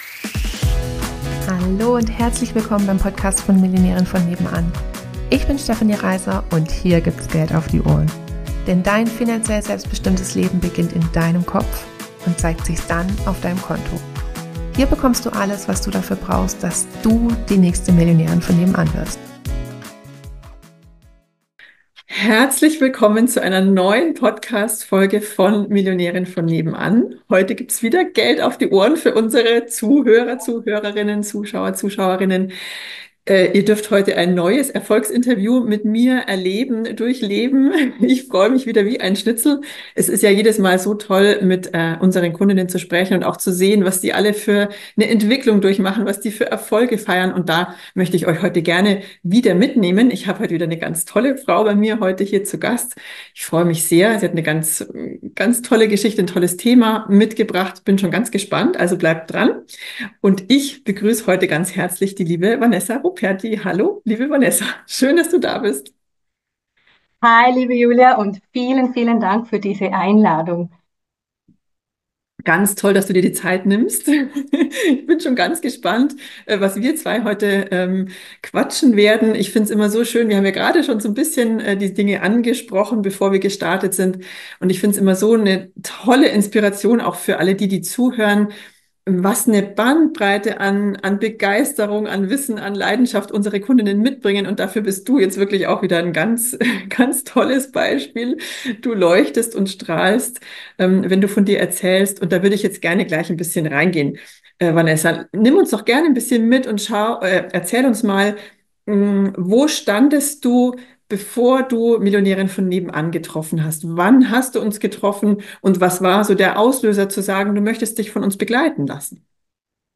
Erfolgsinterview